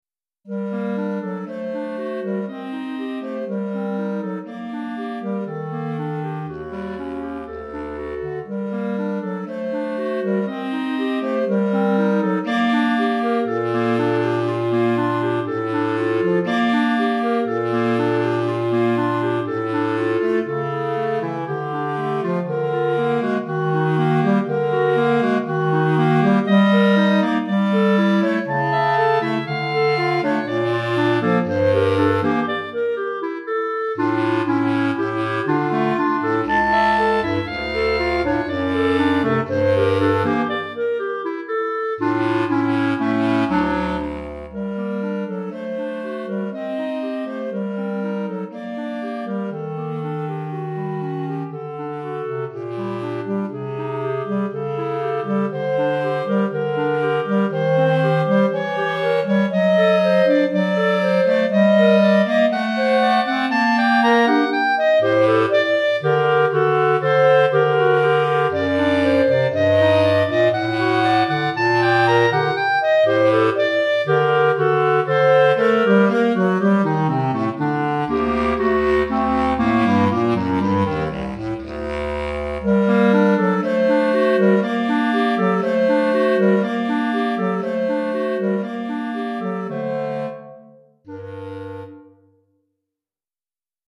2 Clarinettes en Sib et Clarinette Basse